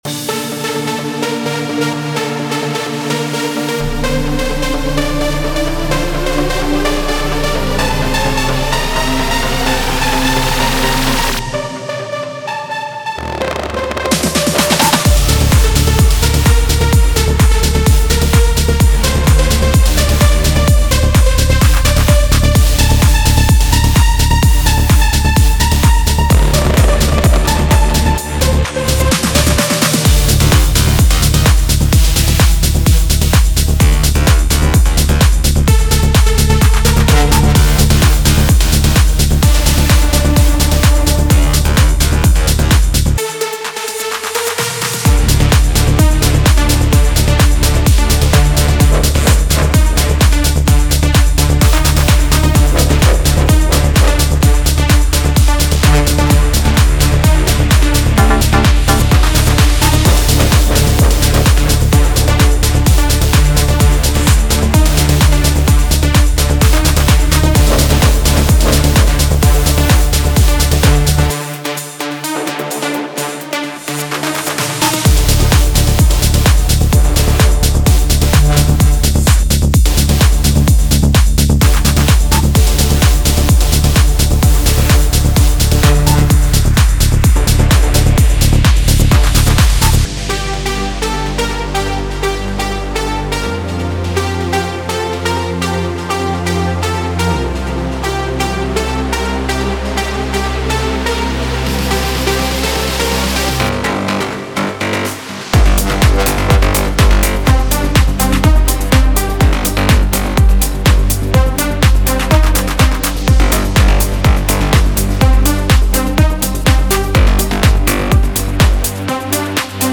Melodic Techno
This premium collection of 70 expertly crafted presets for Xfer Serum is designed to deliver the deep, atmospheric, and driving sounds that define the genre.
14 Bass – Deep, punchy, and growling low-end power
24 Leads – Captivating and expressive melodies
9 Pads – Lush, evolving soundscapes
8 Plucks – Crisp and dynamic synth plucks
15 Stabs – Sharp, energetic synth stabs